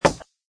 woodplastic2.mp3